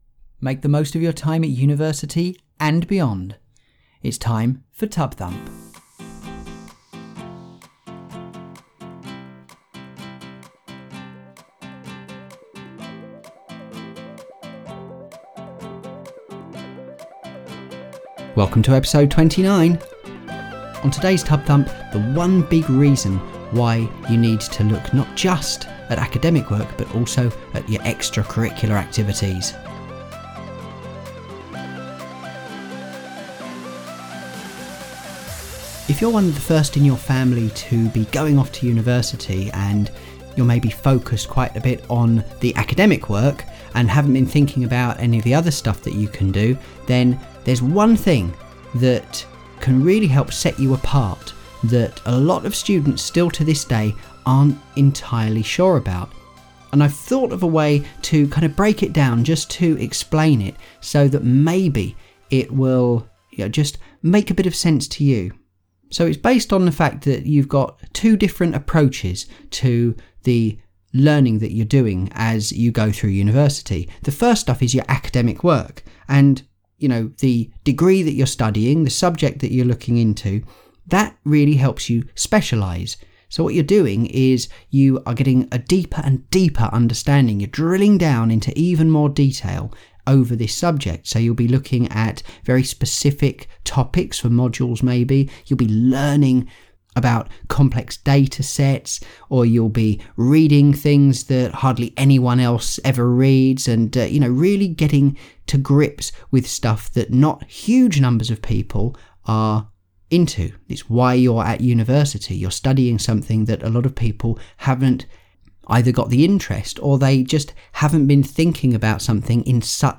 Music for TUB-Thump is Life, by Tobu, which is released under a Creative Commons license.